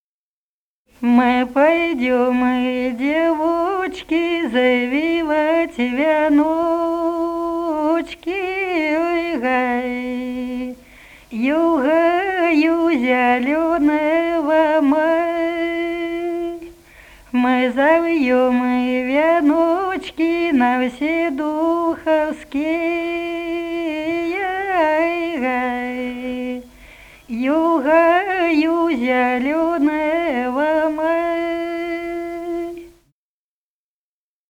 Народные песни Смоленской области
«Мы пойдём, девочки» (майская, троицкая).